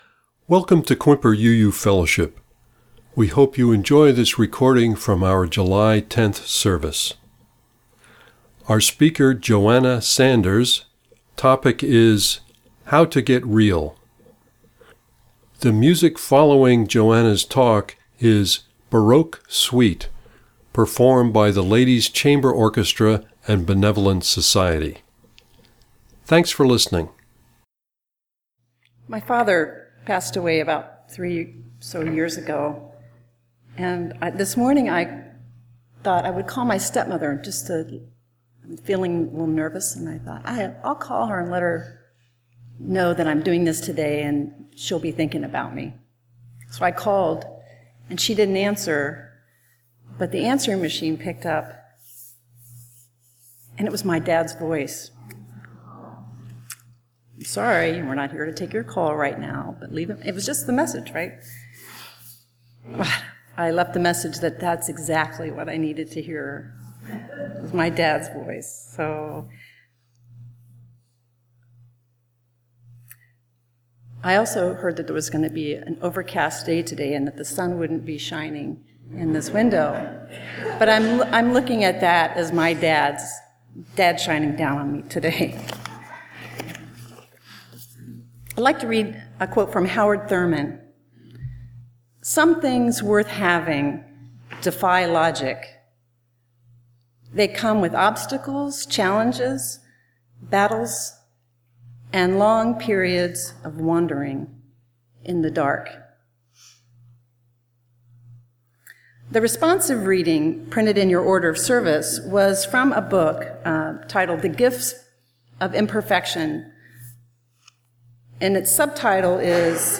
Listen to the introduction and sermon here.